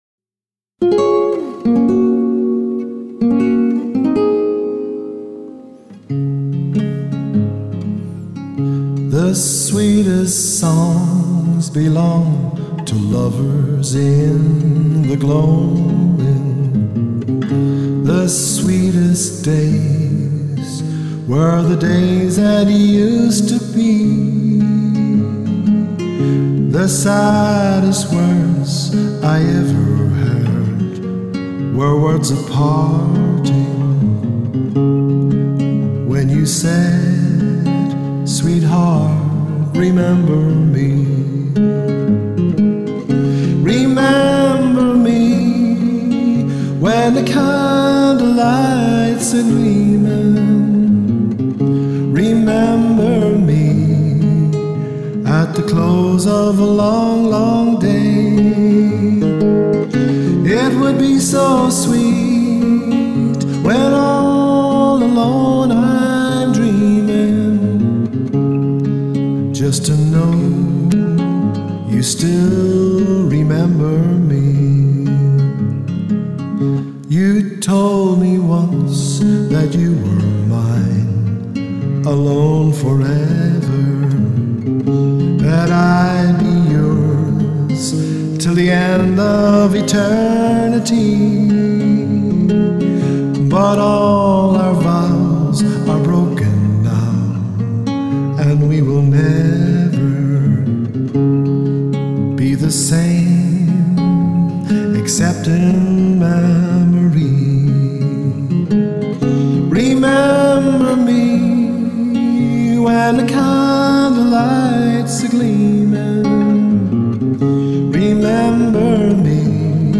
voice & guitar